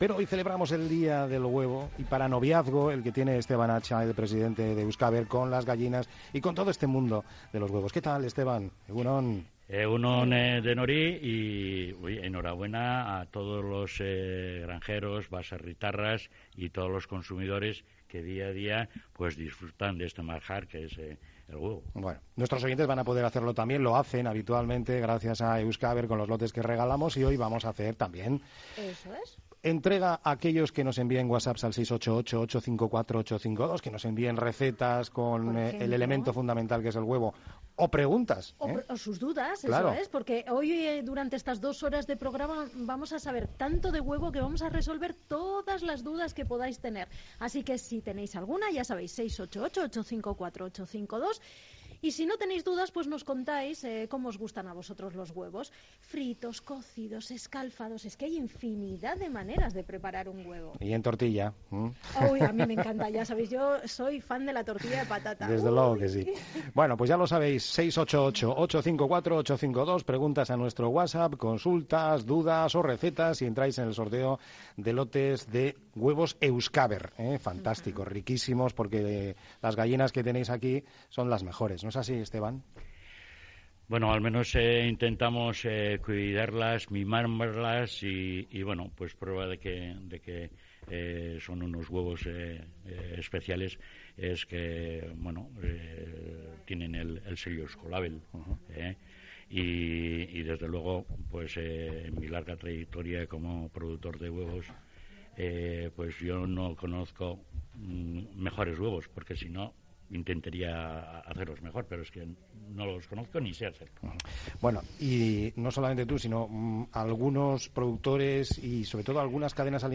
Tomando el pulso a la actualidad en el mediodía más dinámico de la radio.